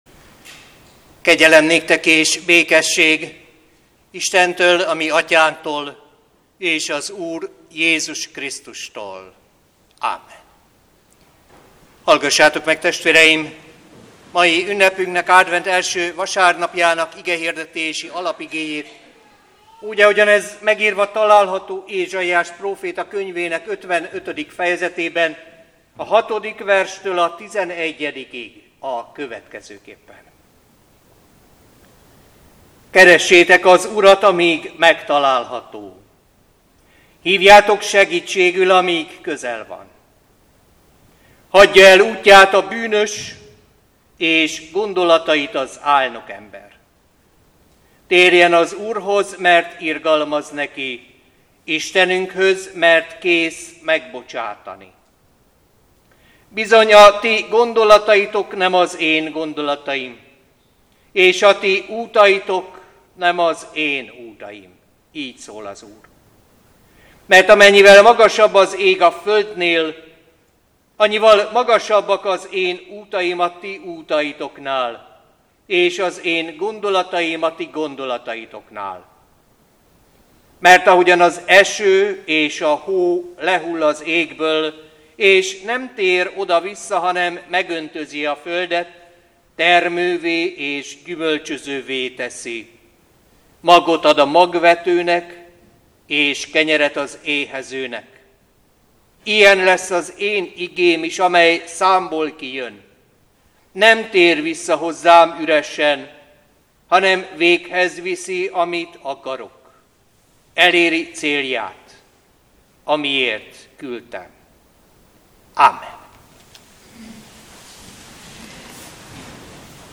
Igehirdetések